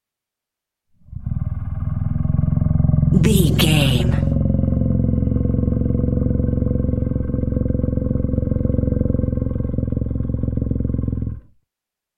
Monster growl epic creature
Sound Effects
scary
ominous
dark
haunting
eerie